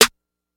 {Snr} way back.wav